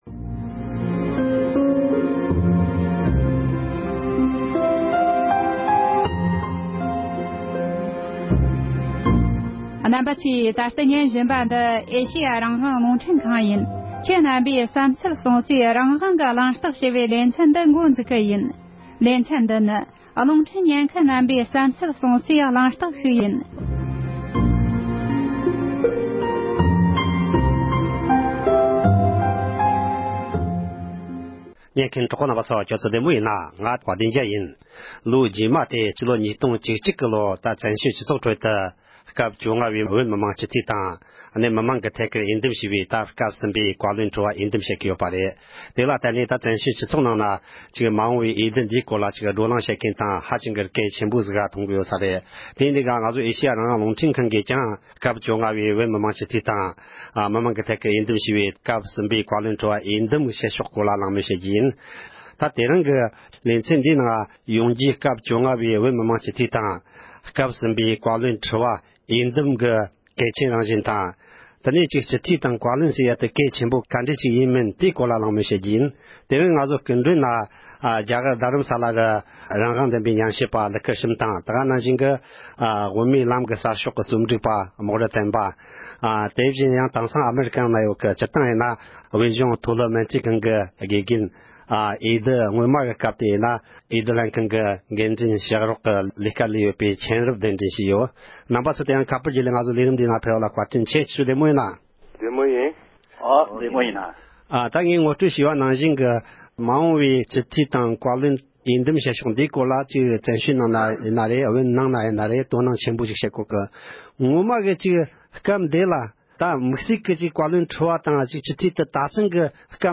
༢༠༡༡ལོའི་བཀའ་བློན་ཁྲི་པ་དང་བོད་མི་མང་སྤྱི་འཐུས་འོས་འདེམས་སྐོར་གྱི་གལ་ཆེའི་གདན་དོན་ཁག་གི་སྐོར་མི་སྣ་ཁག་དང་གླེང་མོལ་ཞུས་པ།